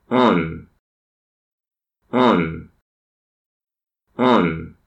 aun (long sound)